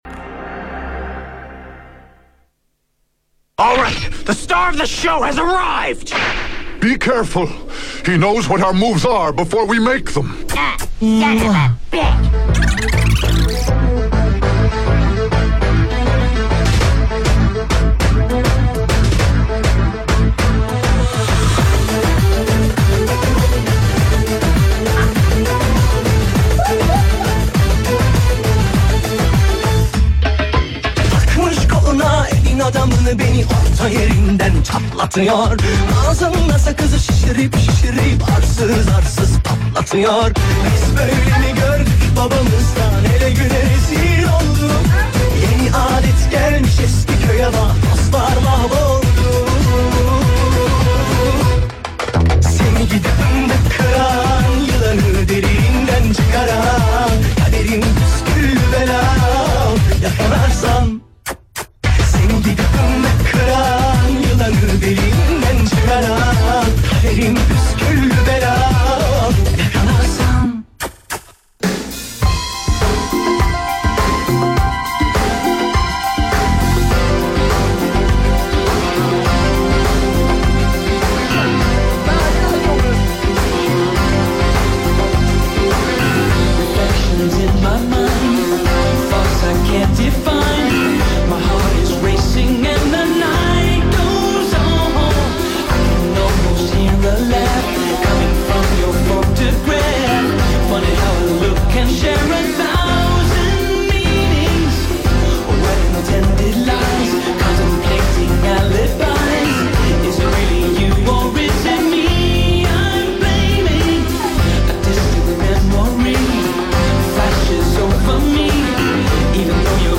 Live Set/DJ mix